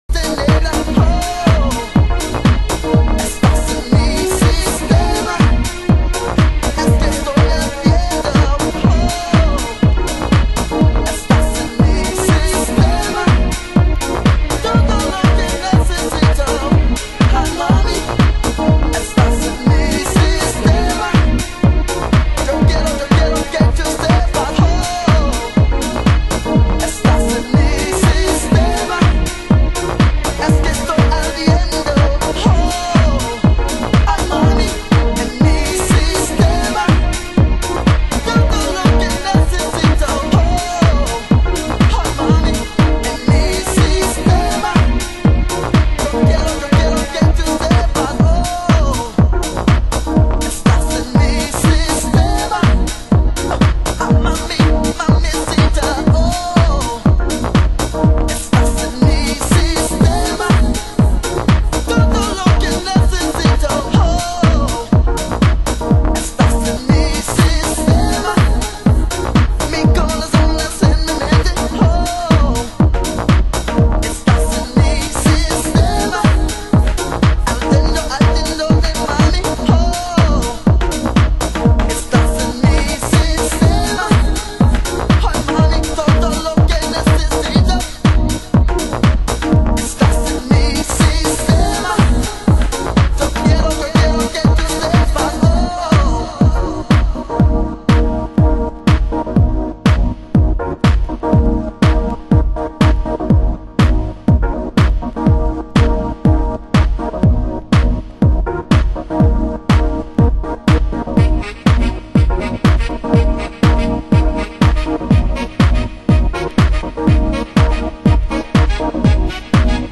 (Atmospheric Vocal)